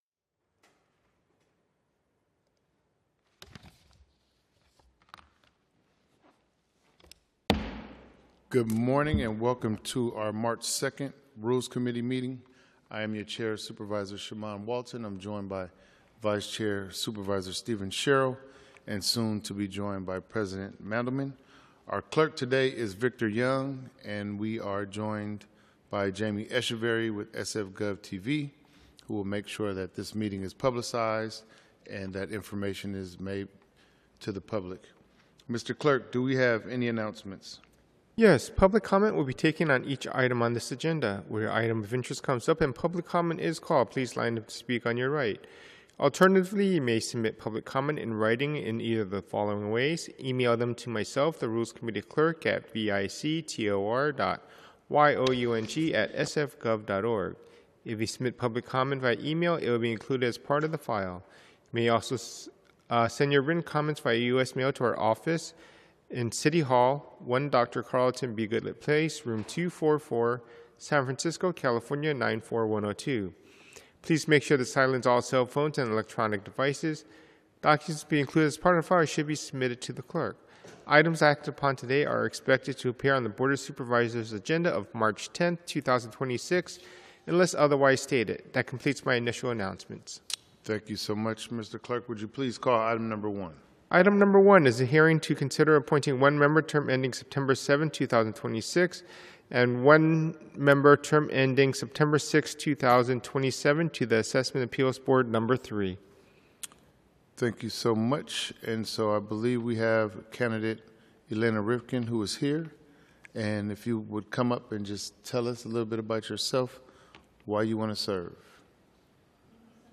Rules Committee - Regular Meeting - Mar 02, 2026